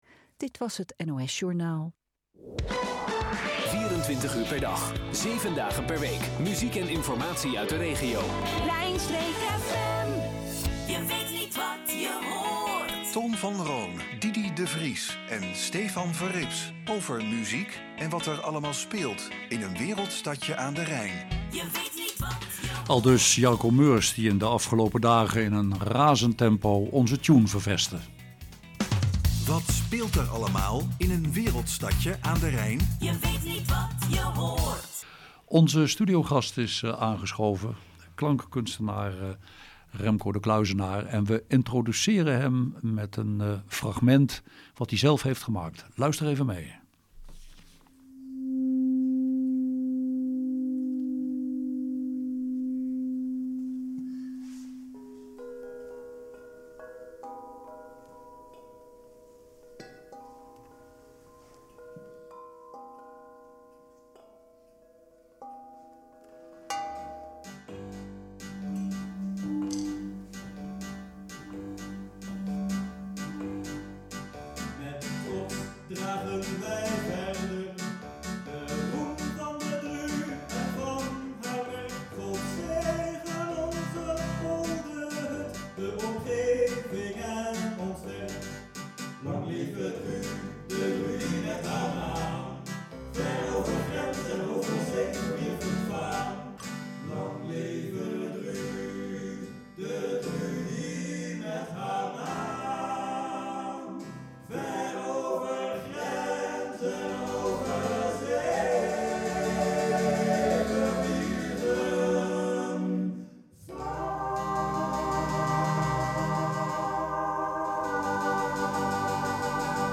Hieronder het interview fragment los van de hele uitzending:
een aangename ontmoeting met drie radiomakers met – ik kan het niet uit mijn hoofd krijgen – toch een lichte “Radio Bergeijk” vibe, wegens het expliciet benoemen van de nieuwe jingle.